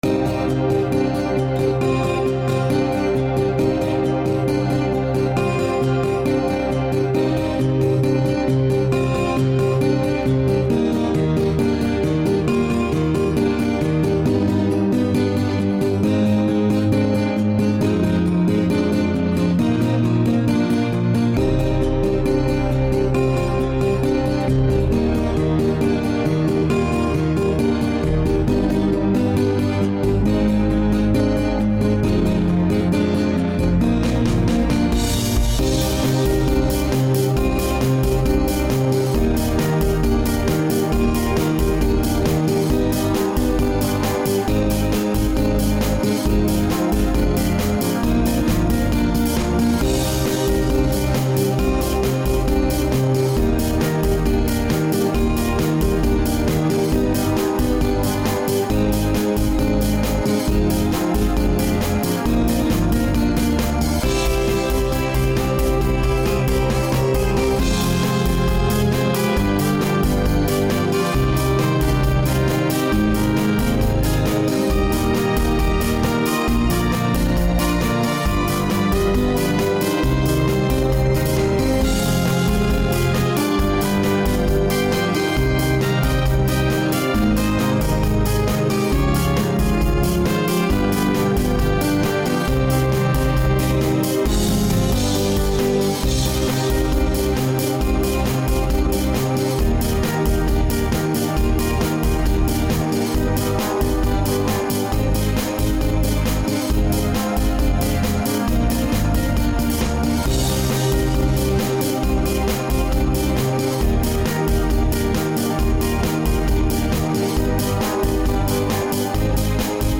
минусовка версия 45497